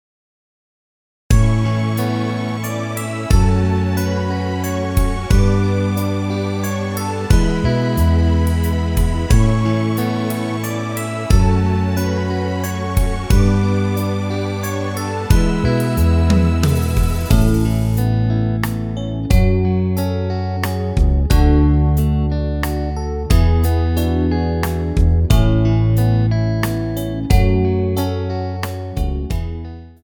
Rubrika: Pop, rock, beat
Karaoke